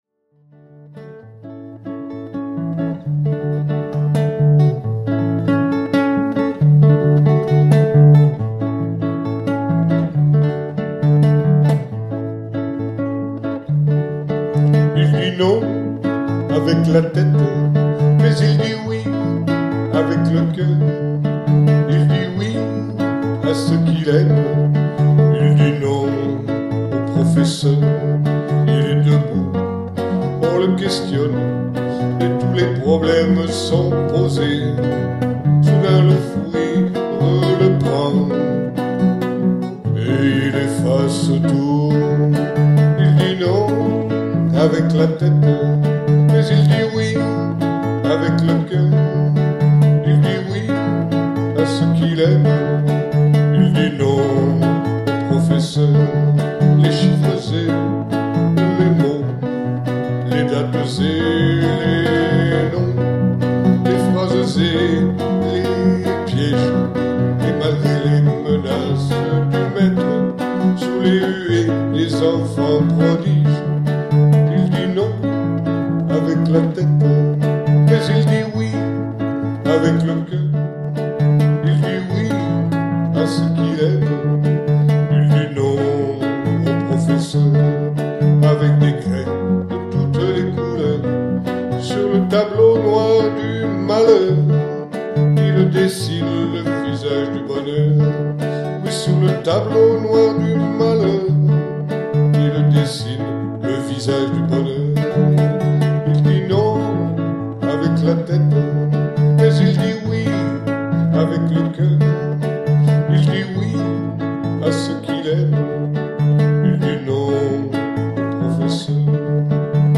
[Capo 2°]